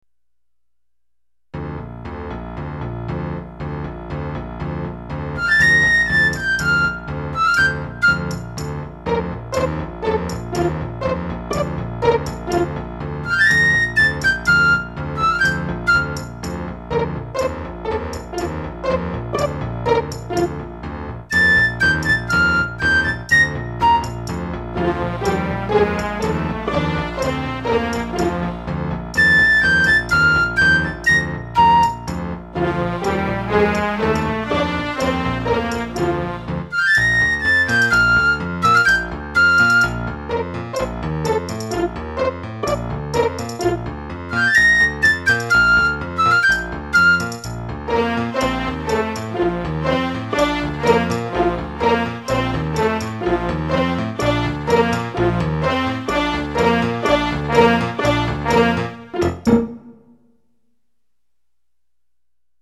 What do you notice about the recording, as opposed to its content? Most of the music has been performed in real-time using an Ensoniq SQ-2 synthesizer/sequencer, multitracking for each instrument's part. (No MIDI has been used to prepare these, except where noted.) Don't be surprised if you hear a mistake or two!